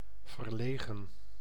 Ääntäminen
IPA: [vǝɾ.le.ɣǝⁿ]
IPA: /vərˈleːɣə(n)/